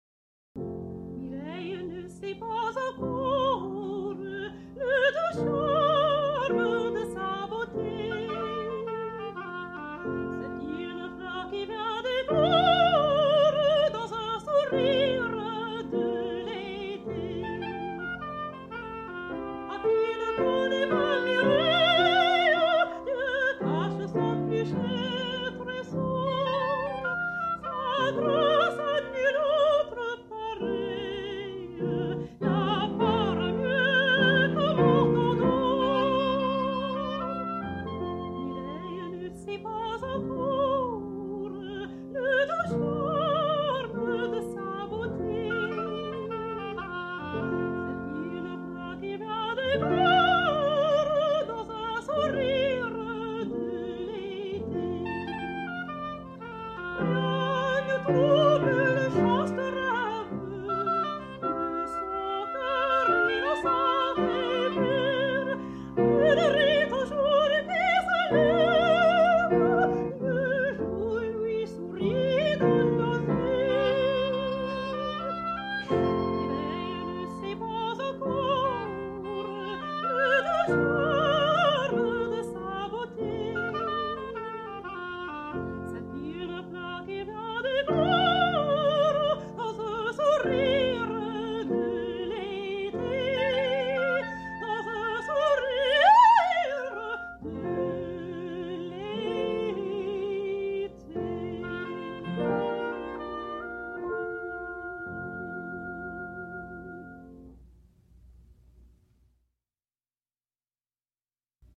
Best known for his numerous operas, and wrote in a generally melodic style.
Voice  (View more Intermediate Voice Music)
Classical (View more Classical Voice Music)